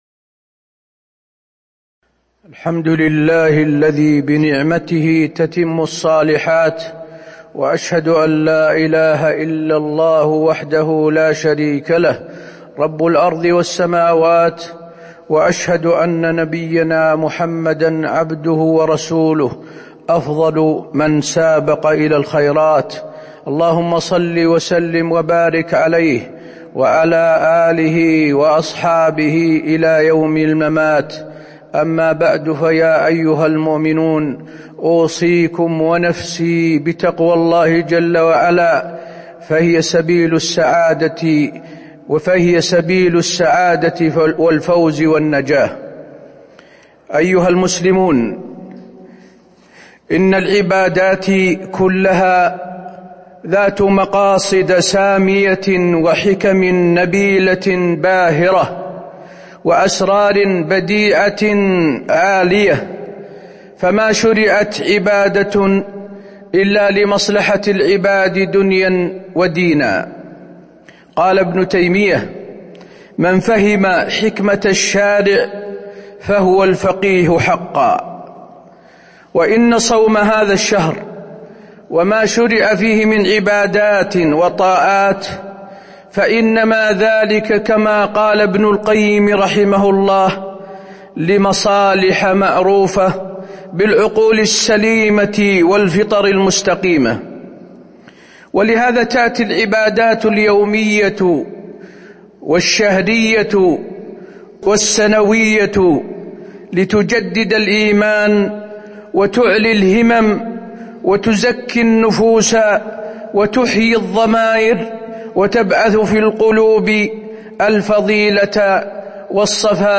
تاريخ النشر ٢٥ رمضان ١٤٤٢ هـ المكان: المسجد النبوي الشيخ: فضيلة الشيخ د. حسين بن عبدالعزيز آل الشيخ فضيلة الشيخ د. حسين بن عبدالعزيز آل الشيخ مقاصد مشروعية الصوم